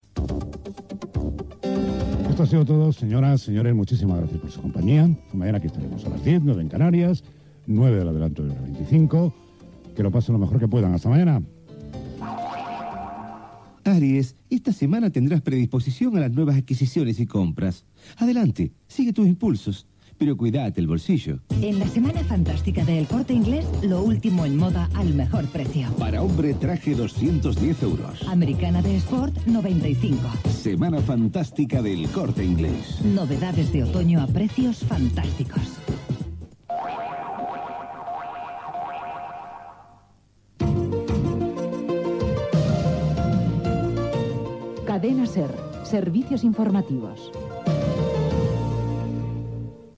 Comiat del programa amb publicitat, senyal identificactiu del programa i careta dels Seveis Informatius de la Cadena SER
Informatiu